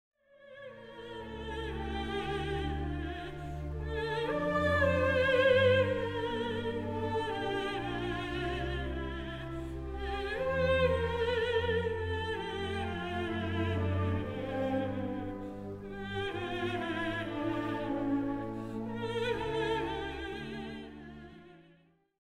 It is filled with what we call the Mannheim sigh, which was a heavy first note in a pair of two, the first being higher: it sounds like a sigh, kind of, here is the same aria by C.P.E. Bach, and they are all over the place: